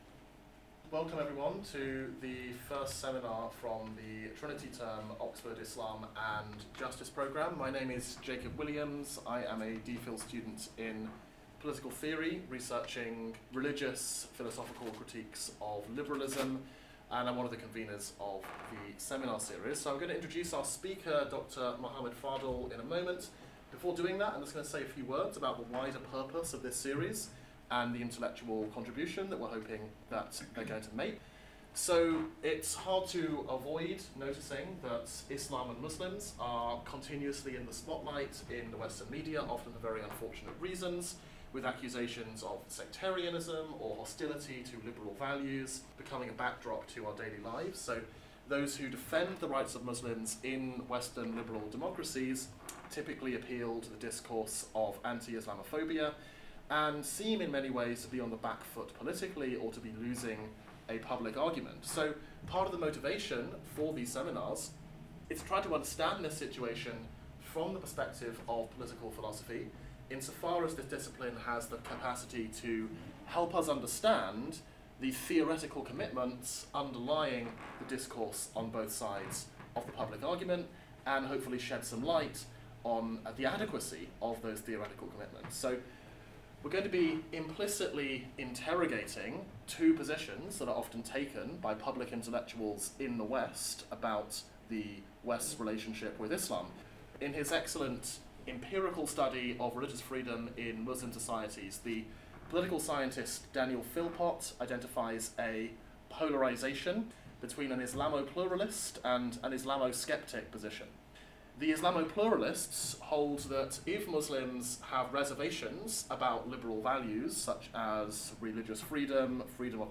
The theme for this week's lecture is Islam and Political Liberalism, specifically Liberal Commitments, Islamic Commitments and Public Reason: Strategies for Principled Reconciliation.